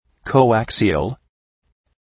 {kəʋ’æksıəl}